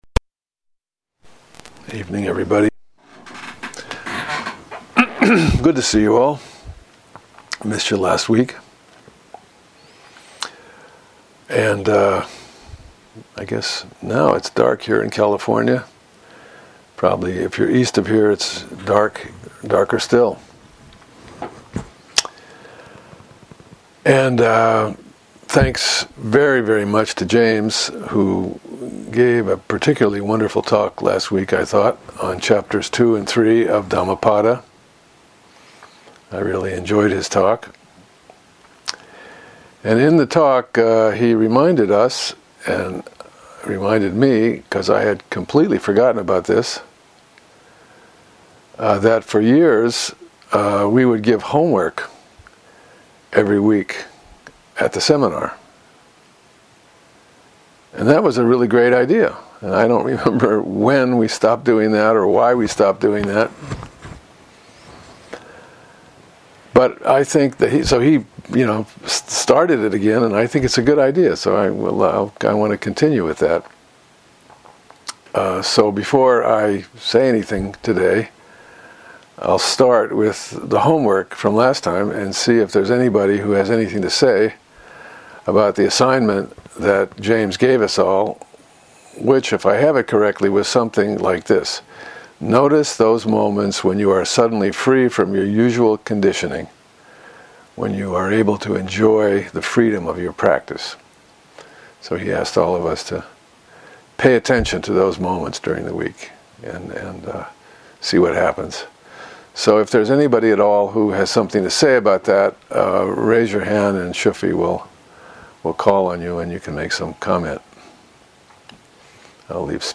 gives the third talk of the Dhammapada series to the Everyday Zen dharma seminar. The Dhammapada or “Path of Dharma” is a collection of verses in the Pali Canon that encapsulates the Buddha’s teachings on ethics, meditation and wisdom and emphasizes practical guidance for living a virtuous life.